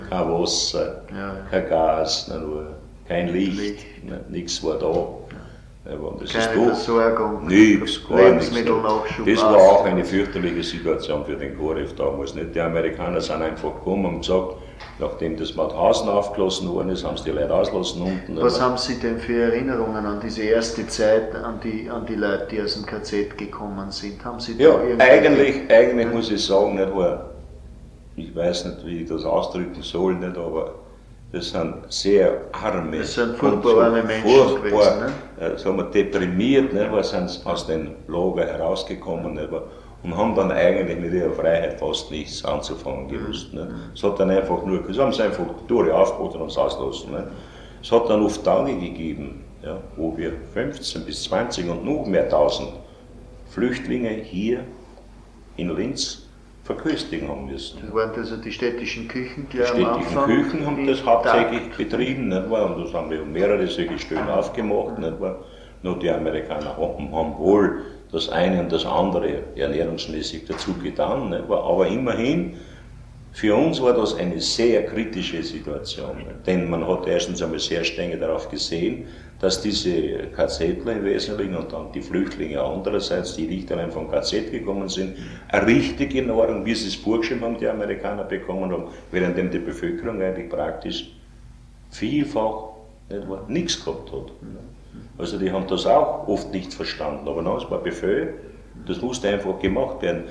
Interview mit Albin Gebhart zur Versorgungslage
Albin Gebhart, damals Vizebürgermeister und Personalreferent, berichtet über die katastrophale Versorgungslage unmittelbar nach Kriegsende, über die befreiten KZ-Häftlinge und über die Verköstigung der Flüchtlinge durch die Stadtverwaltung.